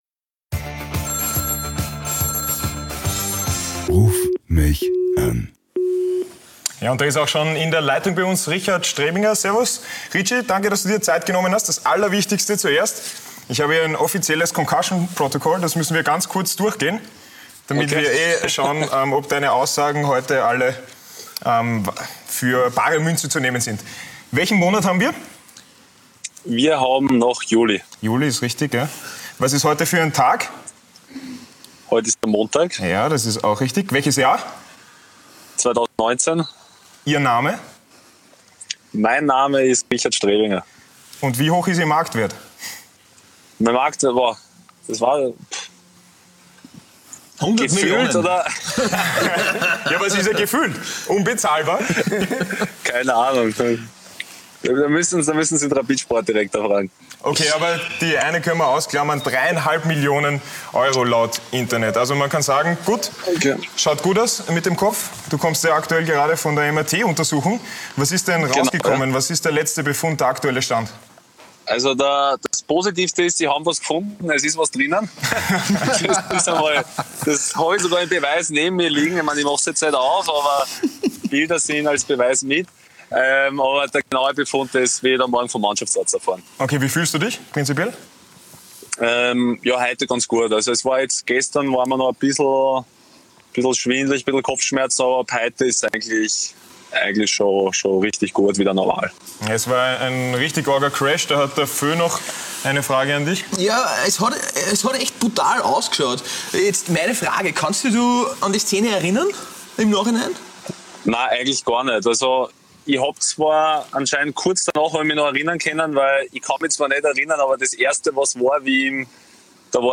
Jede Woche rufen sie im Rahmen der TV-Show einen prominenten Fußballer oder Sportler via Facetime an und plaudern mit ihm über alles.